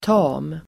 Ladda ner uttalet
tam adjektiv (bildligt även " kraftlös"), tame [also used figuratively, " powerless"] Uttal: [ta:m]